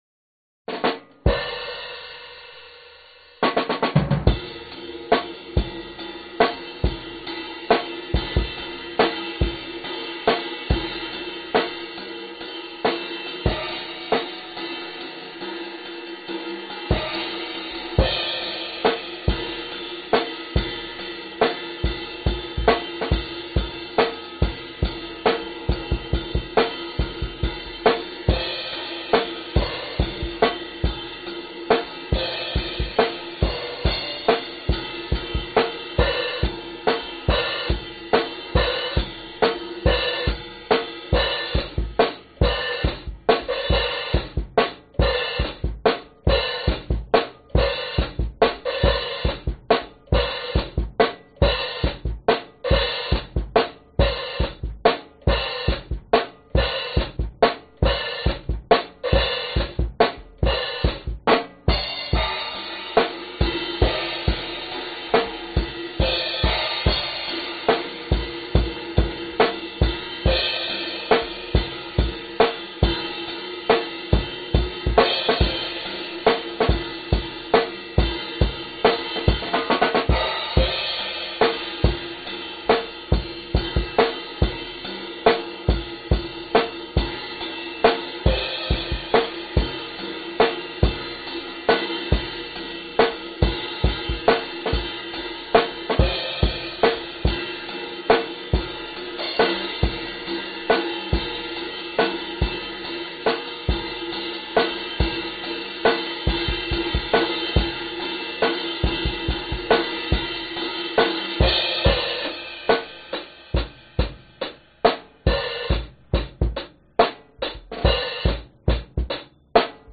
描述：助推器驾驶检查器
Tag: 起动器 ECU 发动机